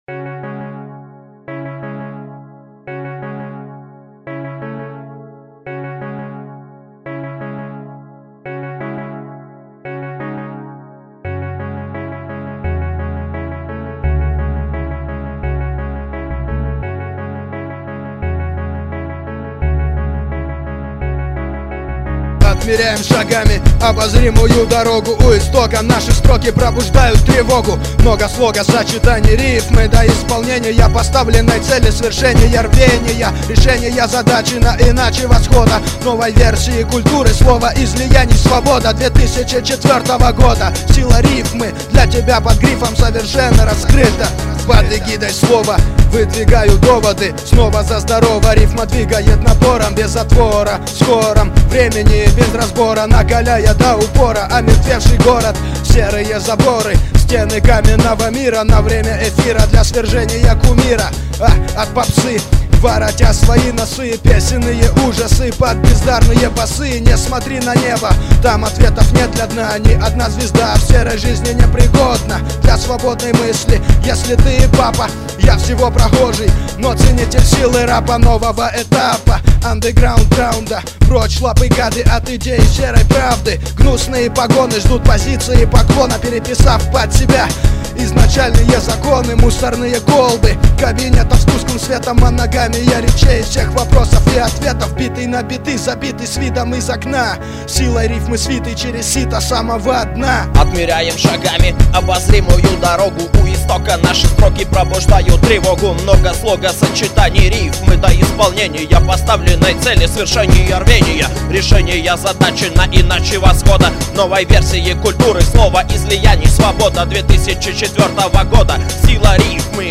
undeground rap